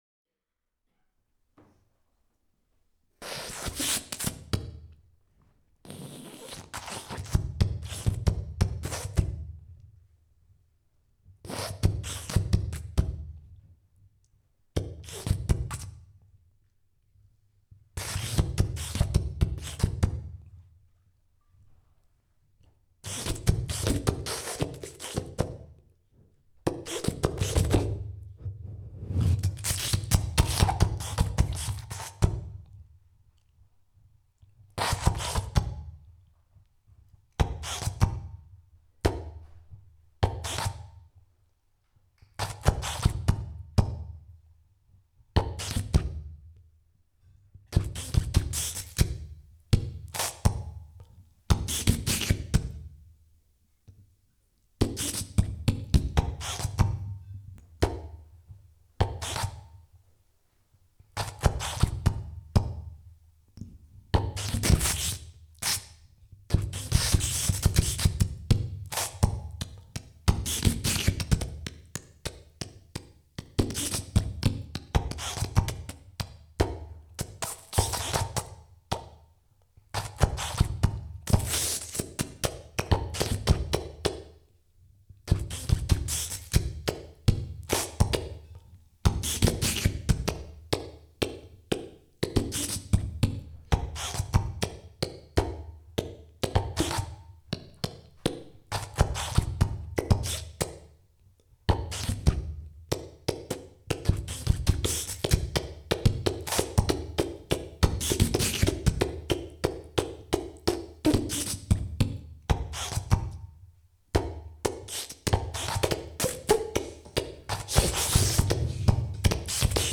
experimental musician and exhibiting artist